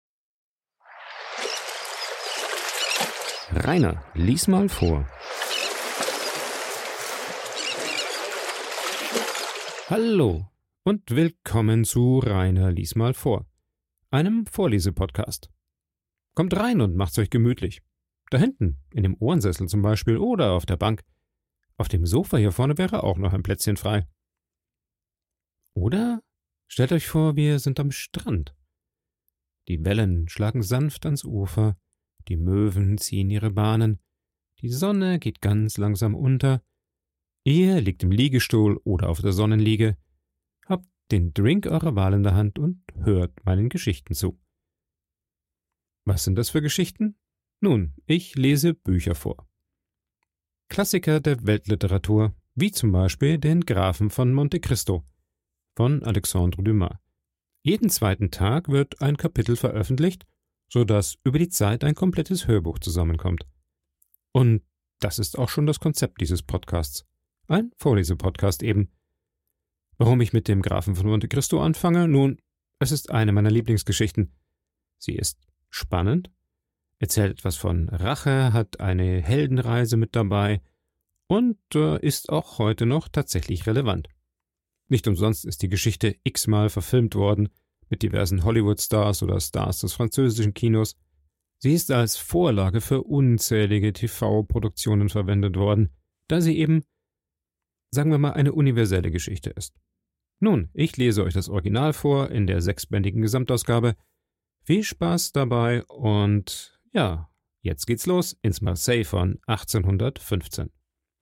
Der Vorlese Podcast
Ein Vorlese Podcast